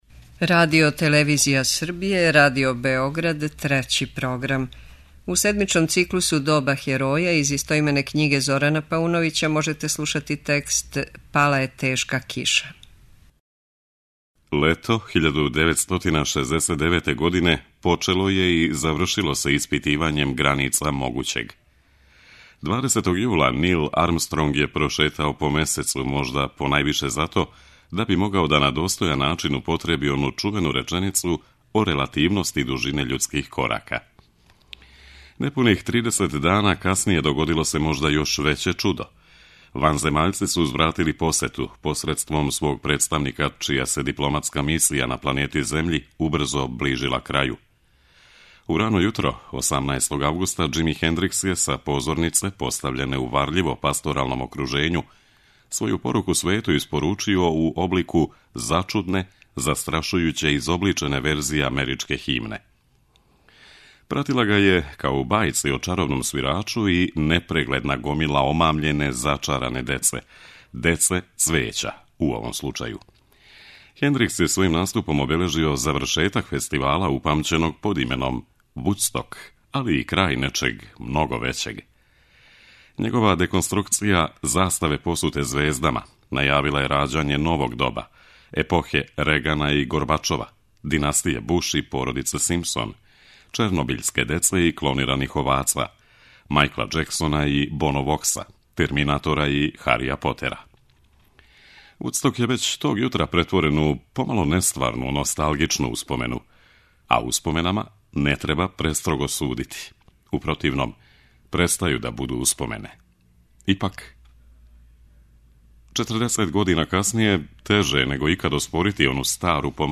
преузми : 9.55 MB Рефлексије Autor: Уредници Трећег програма У емисији се презентују краћи критички текстови који у форми радио-есеја анализирају савремена кретања и тенденције у различитим областима интетелектуалног и духовног стваралаштва, од савремене филозофије и других хуманистичких дисциплина, до архитектуре, књижевности и уметности.